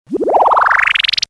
B_CRACHE.mp3